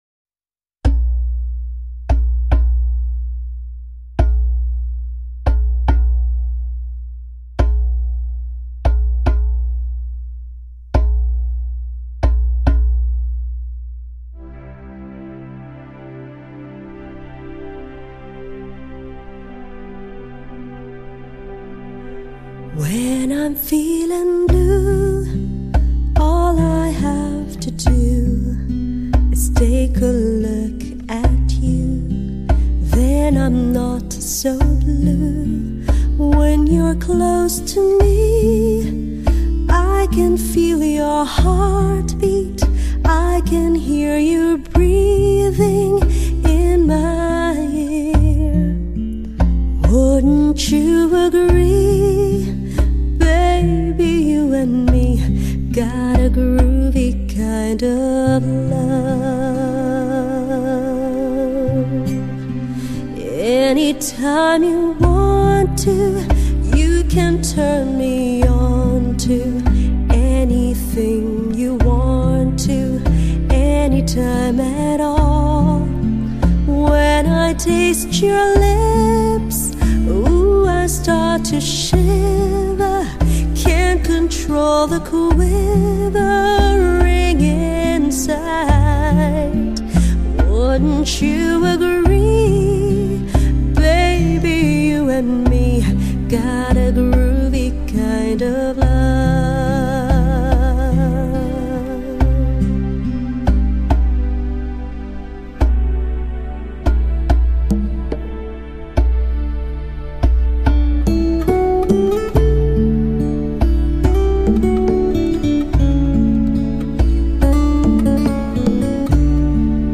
Hi-Fi/试音 >> 发烧天碟
謝謝一版的高檔酒吧音樂